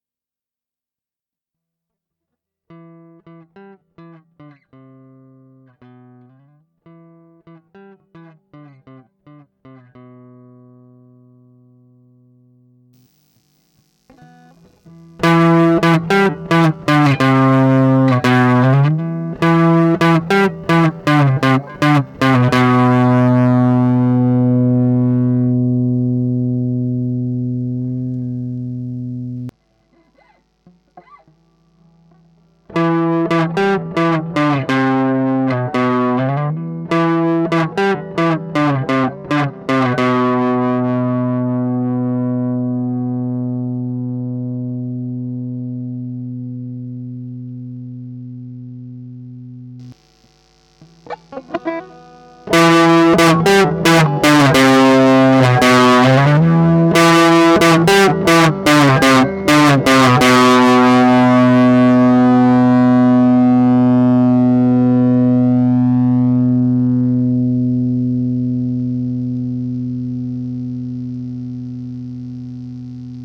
Hier 2 MP3s, die ich über den PAD Eingang meines Toneports UX2 (ohne Effekte oder Verstärkung) aufgenommen habe. Die Aufnahme selbst cippt nicht. Wenn, dann ist das der Amp.